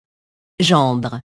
gendre [ʒɑ̃dr] nom masculin (lat. gener, -eris)